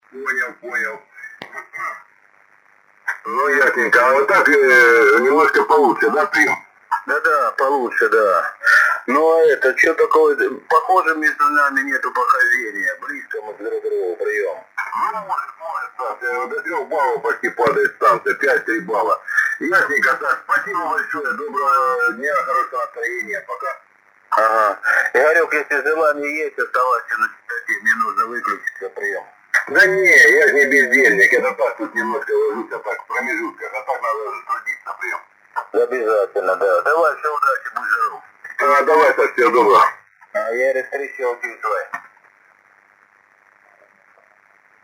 Запись велась: в CW 200Hz, в SSB 2,4kHz.
Продолжаю выкладывать записи работы приемника (tr)uSDX-a. Запись делалась во время проведения IARU HF Championship.
SSB: